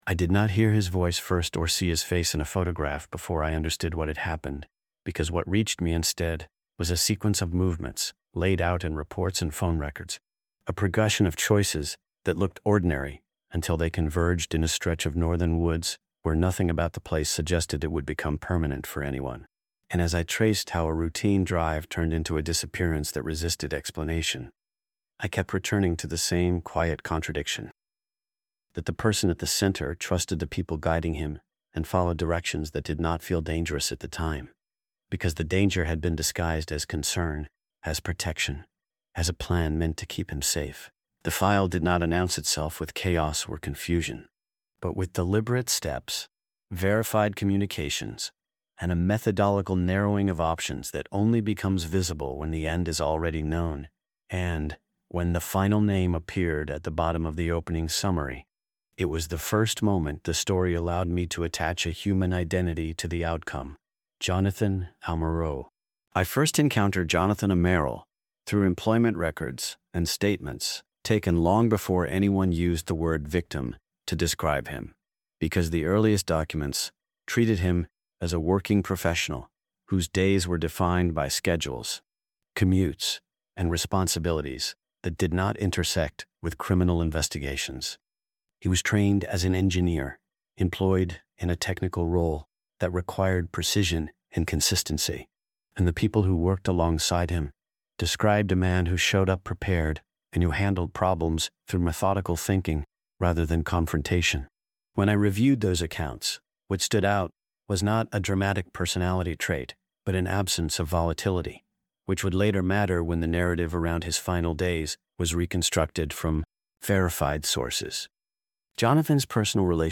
true-crime audiobook
Told in a first-person investigative voice, the story follows the case from its earliest records through the transition from missing-person inquiry to homicide investigation, relying on court filings, digital records, forensic findings, and sworn testimony.